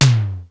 Index of /kb6/Akai_XR-10/Percussion
Tom L-02.wav